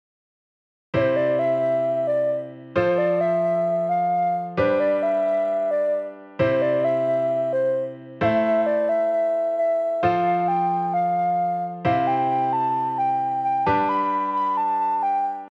↓の音源は、Aメロは「1小節のフレーズx4」が元になっているので、Bメロは「2小節のフレーズx2」にしてみた例です。
（コード進行はAメロ＝C⇒F⇒G⇒C、Bメロ＝Am⇒F⇒C⇒Gです）